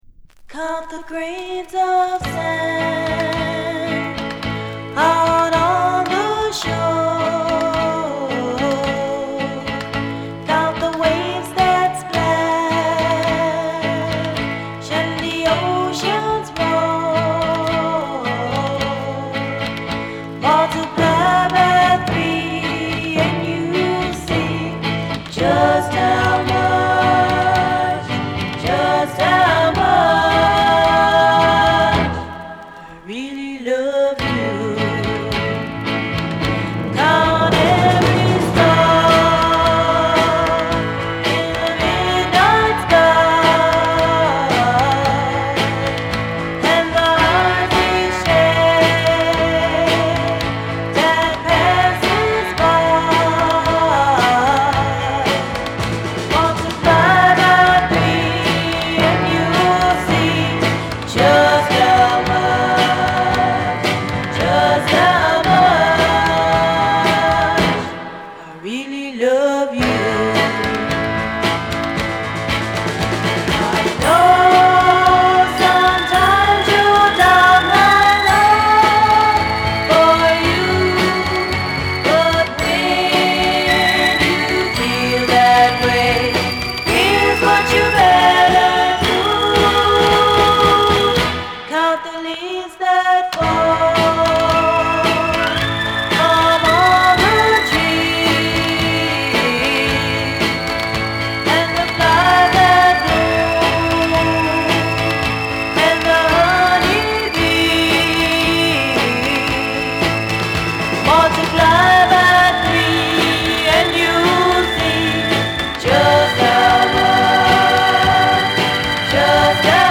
希望に満ちたヴォーカルに華々しいホーンを絡めたアップテンポ・ガールズ・サウンドが全面に響き渡る。